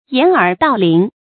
注音：ㄧㄢˇ ㄦˇ ㄉㄠˋ ㄌㄧㄥˊ
掩耳盜鈴的讀法